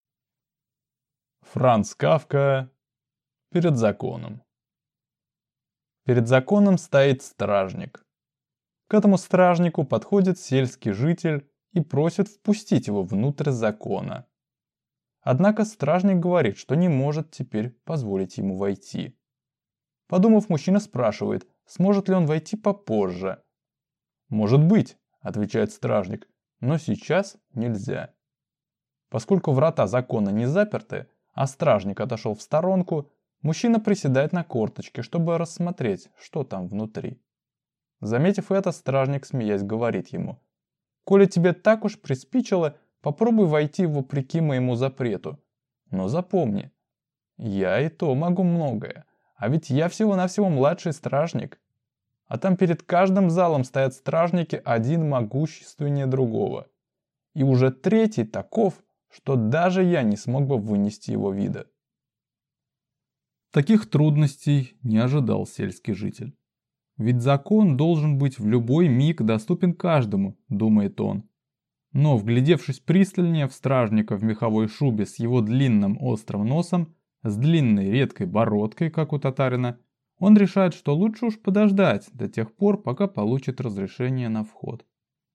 Аудиокнига Перед Законом | Библиотека аудиокниг